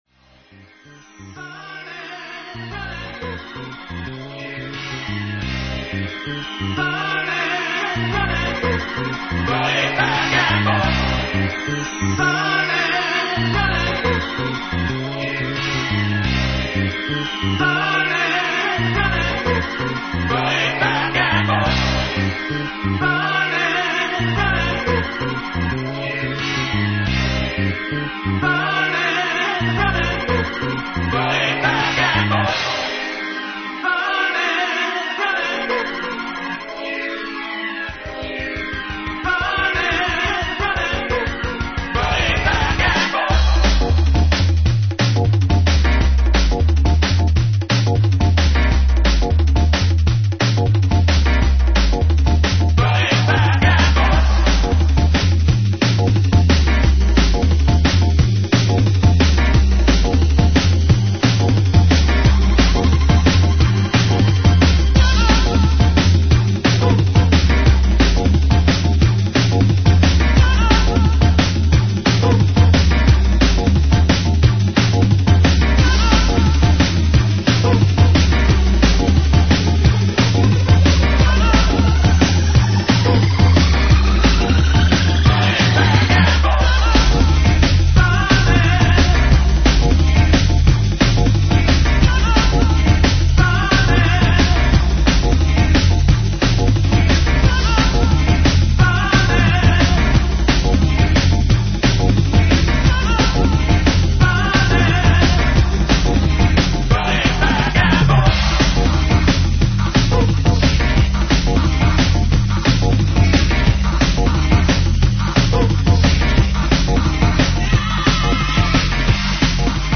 Taste a half an hour of smooth broken beats